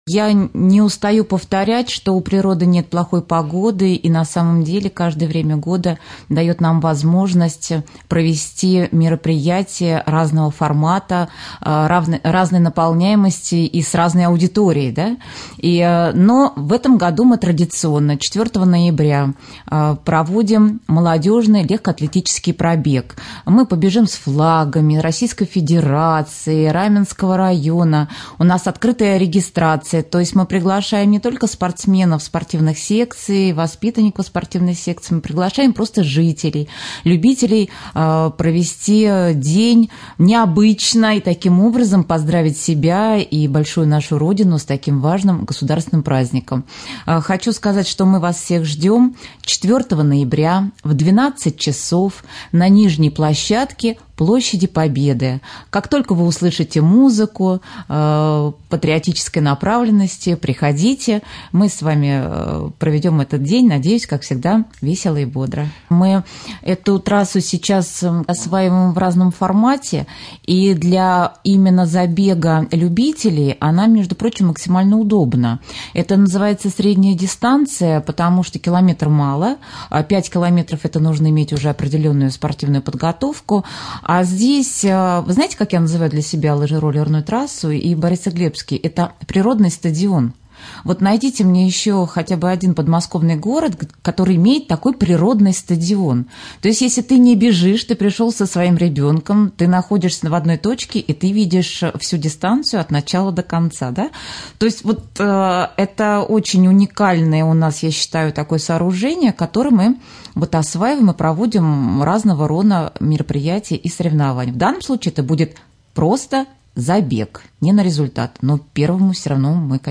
4 ноября в Раменском пройдет молодежный легкоатлетический пробег. Об этом в прямом эфире Раменского радио 1 ноября рассказала председатель Комитета социального развития, спорта и молодежной политики администрации Раменского района Елена Володина. Колонна участников пробега пронесет через дистанцию флаги Российской Федерации, Московской области и Раменского района.